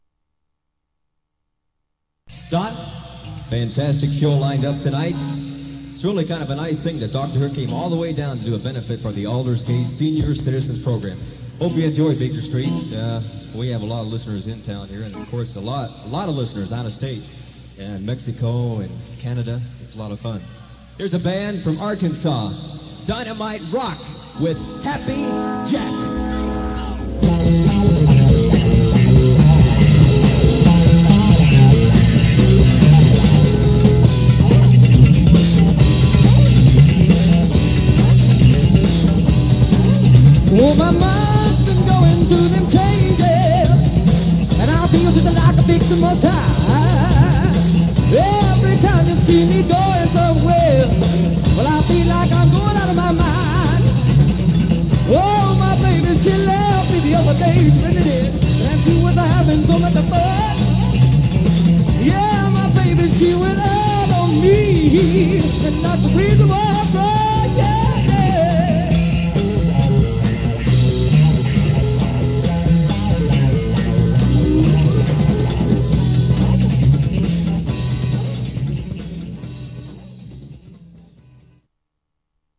keys
percussion
drums
guitar
bass
live in concert at Little Rock's Barton Coliseum